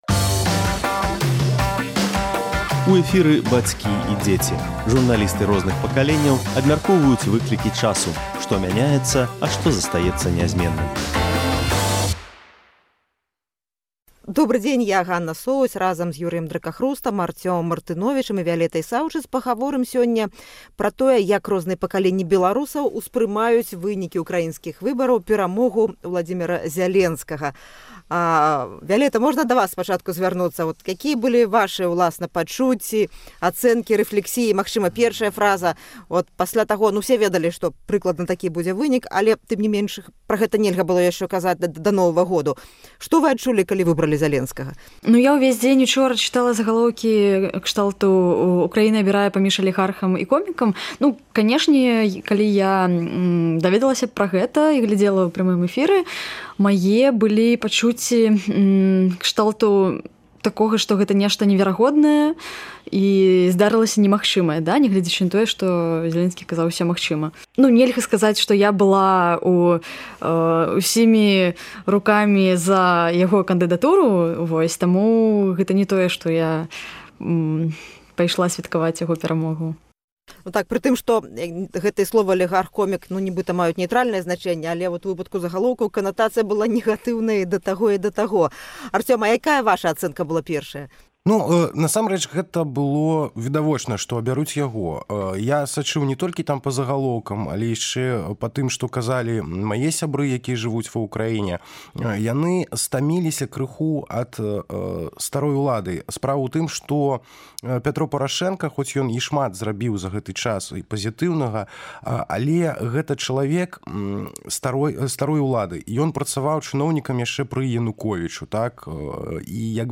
Бацькі і дзеці. Журналісты розных пакаленьняў абмяркоўваюць выклікі часу — што мяняецца, а што застаецца нязьменным.